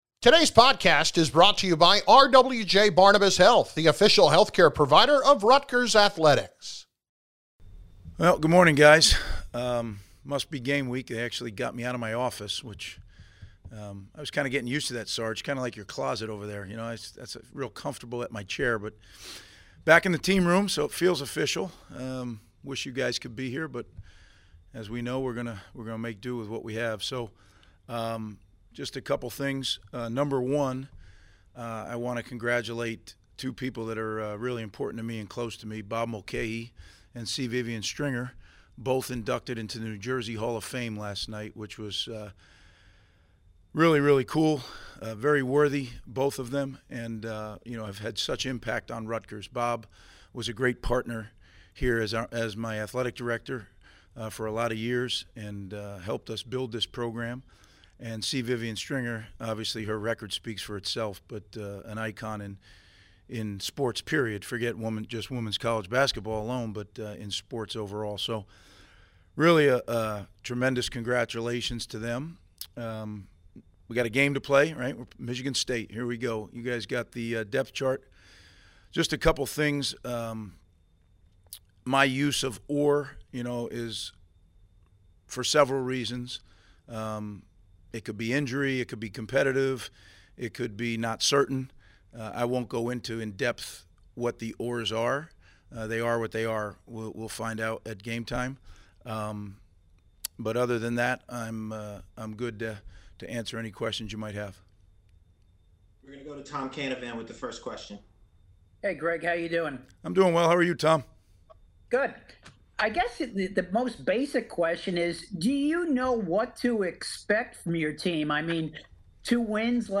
October 19, 2020 Head coach Greg Schiano met with the media to preview the game at Michigan State.